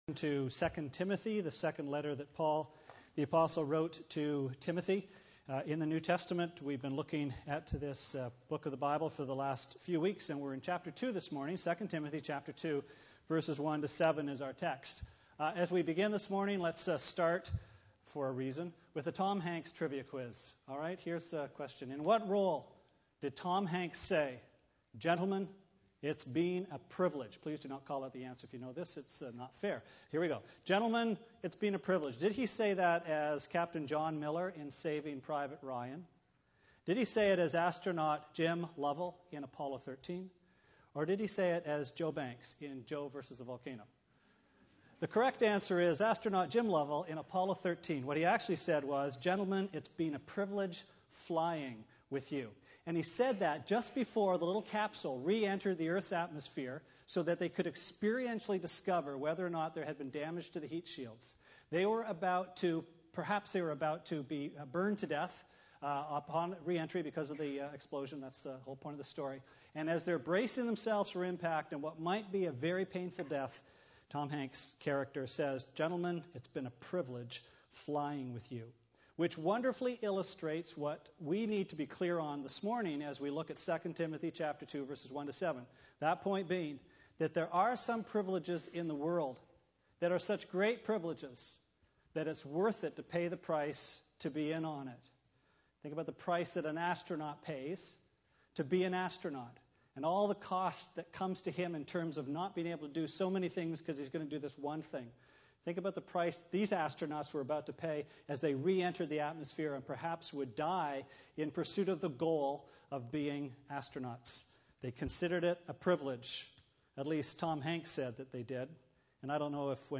Sermon Archives - West London Alliance Church
We continue our verse-by-verse study of Paul's second letter to Timothy in a sermon series entitled "Pay the Price".